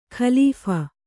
♪ khalīpha